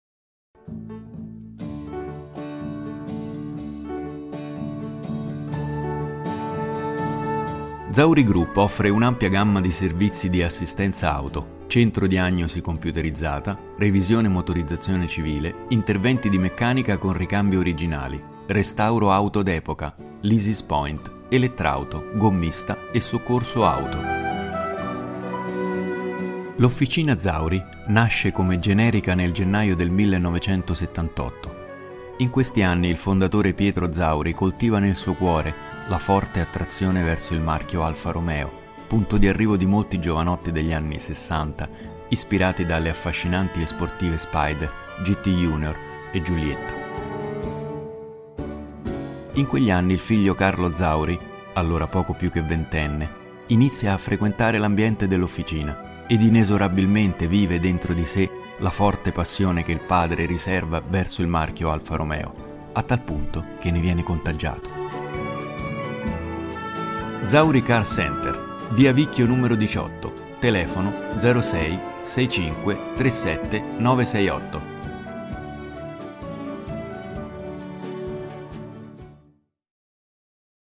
La mia voce è amichevole, informale ma anche professionale.
Amichevole, professionale e business-oriented
Sprechprobe: Industrie (Muttersprache):